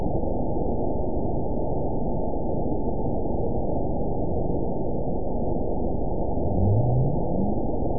event 922753 date 03/25/25 time 18:34:31 GMT (2 months, 3 weeks ago) score 9.44 location TSS-AB01 detected by nrw target species NRW annotations +NRW Spectrogram: Frequency (kHz) vs. Time (s) audio not available .wav